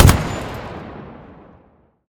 gun-turret-shot-4.ogg